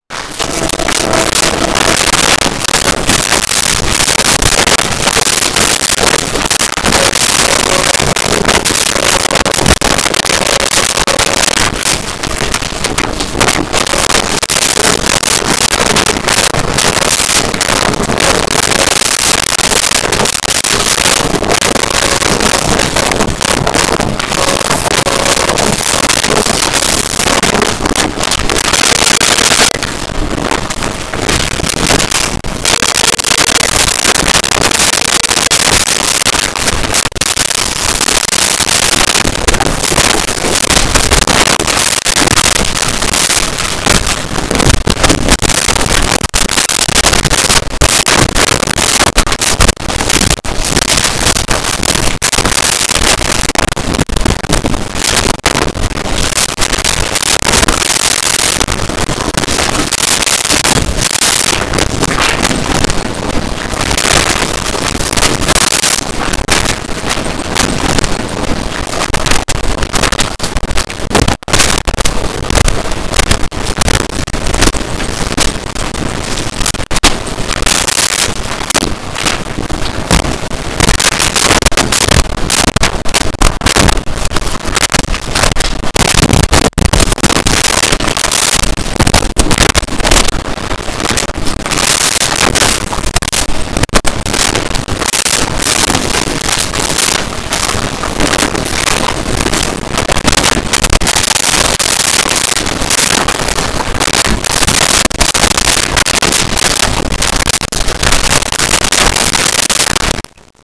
Office Uk Walla
Office UK Walla.wav